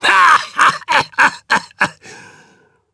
Phillop-Vox-Laugh_jp.wav